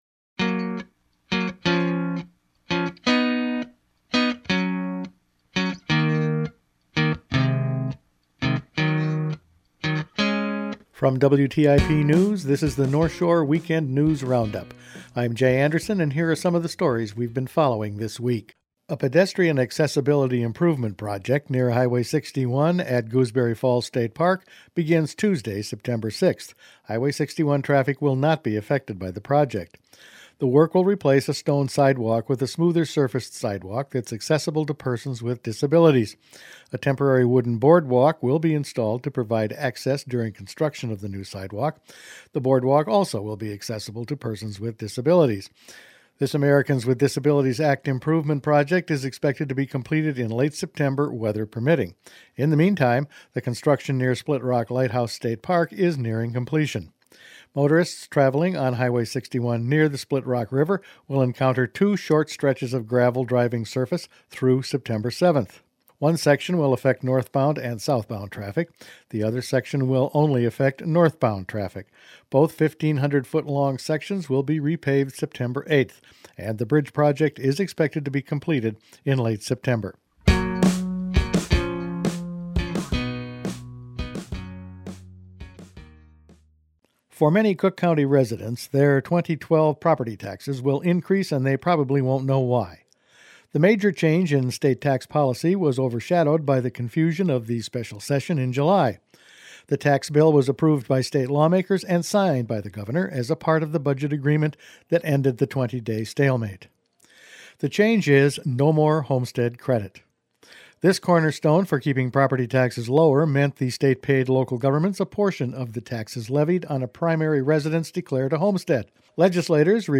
Weekend News Roundup for Sept. 3